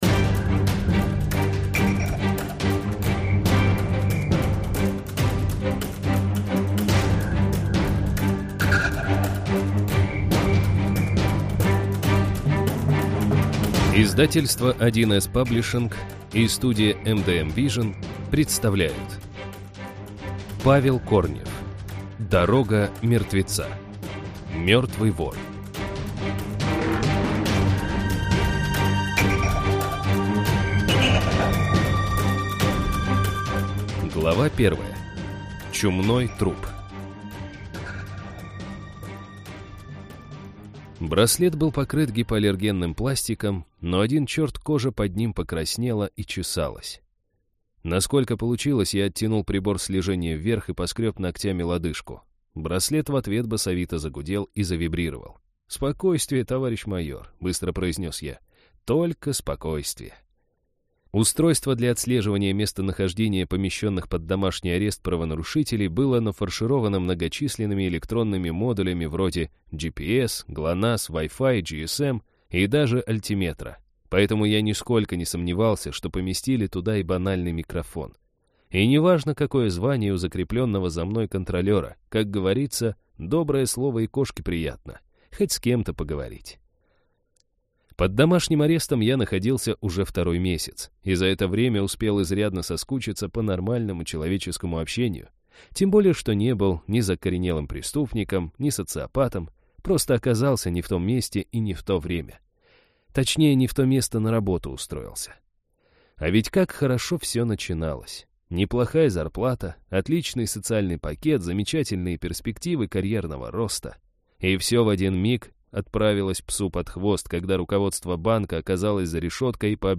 Аудиокнига Мертвый вор - купить, скачать и слушать онлайн | КнигоПоиск